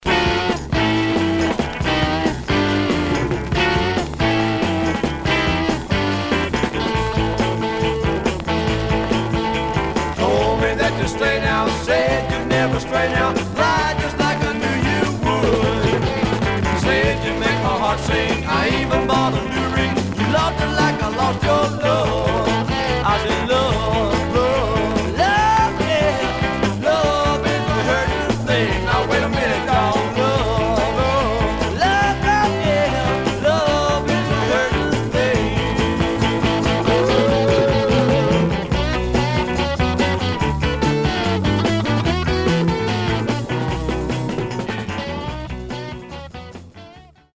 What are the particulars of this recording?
Surface noise/wear Stereo/mono Mono